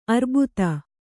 ♪ arbuta